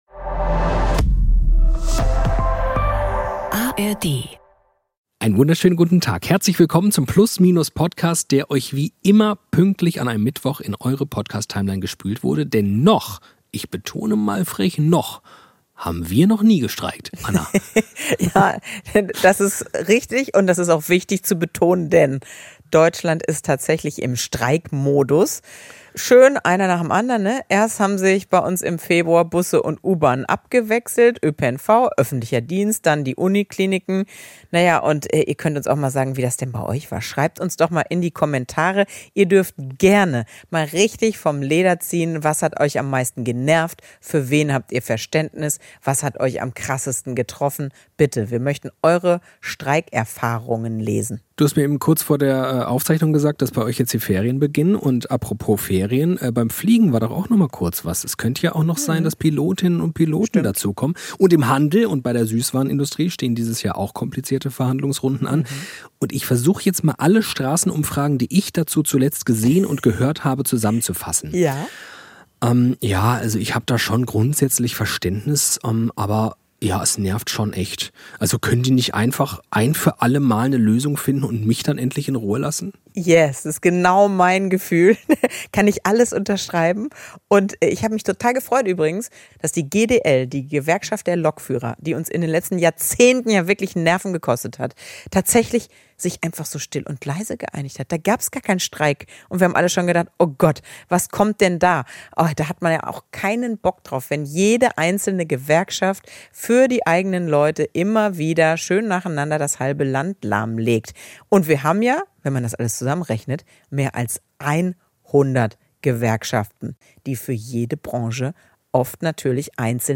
Fallbeispiele, Studien und Stimmen von Gewerkschaften, Arbeitgebern und einem Betriebsrat zeigen: Der Organisationsgrad sinkt, die Streiks werden lauter, die Akzeptanz bröckelt.